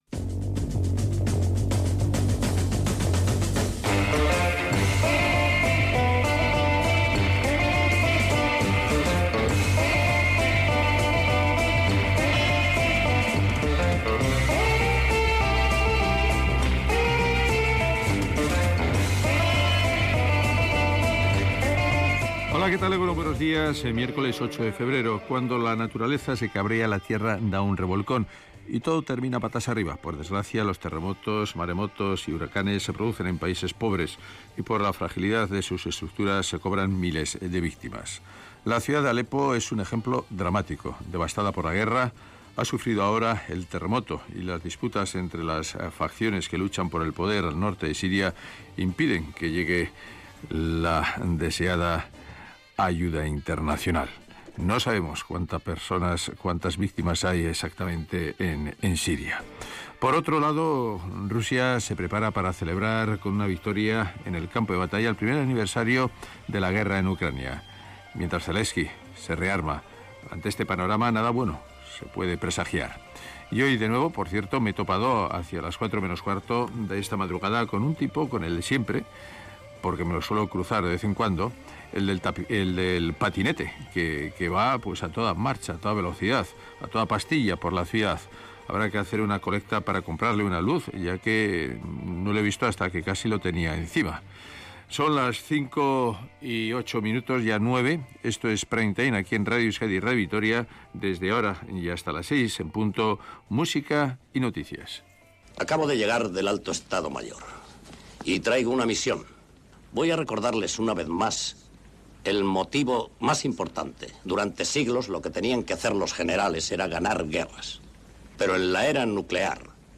Es el despertador de Radio Vitoria con información puntual, el tiempo, el estado de las carreteras y todo ello acompañado de buena música.